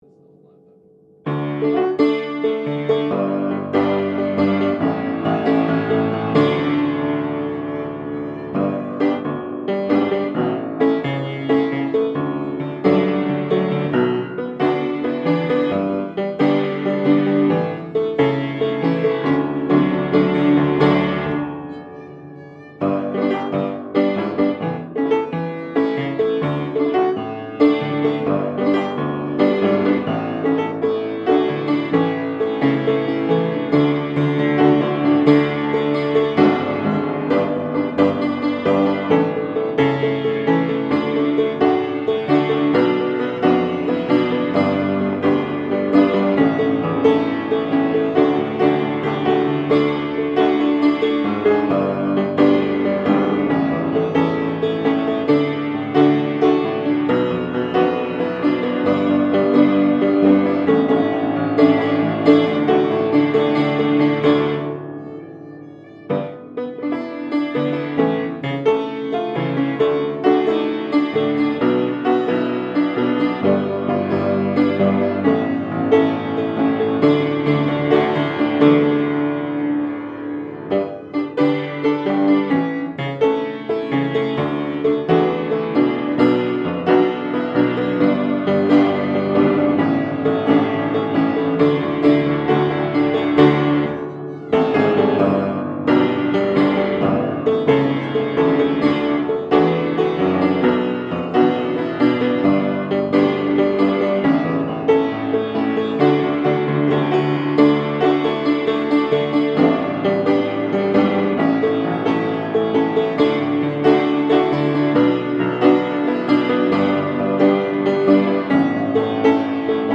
12_9 rehearsal 2 feliz navidad (piano)
12_9+rehearsal+2+feliz+navidad+(piano).mp3